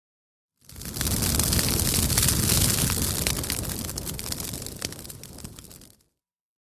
Brush Fire|Exterior
FIRE BRUSH FIRE: EXT: Bursts of roaring, crackly fire, audible fire convection.. Fire Burn.